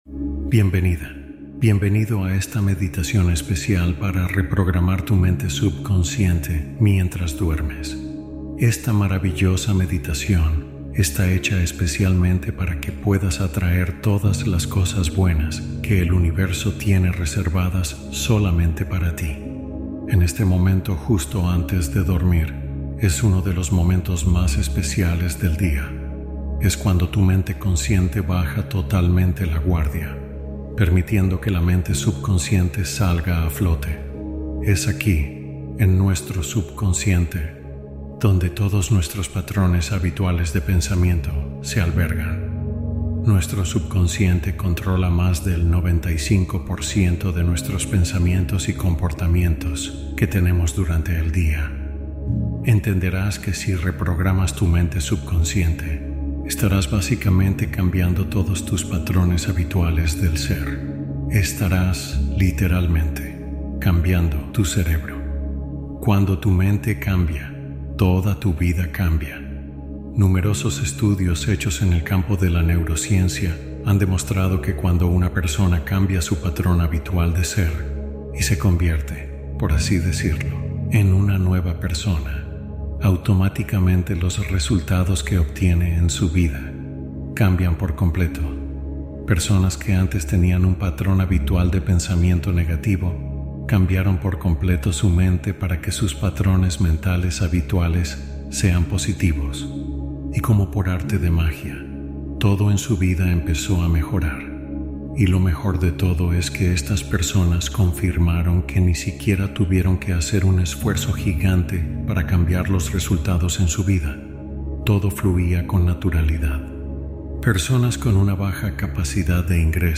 Meditación para Dormir Medita y Atrae Abundancia Mientras Descansas Nov 28 2025 | 01:19:49 1x 00:00 / 01:19:49 Subscribe Share Spotify RSS Feed Share Link Embed